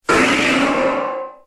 Cri de Méga-Ectoplasma dans Pokémon X et Y.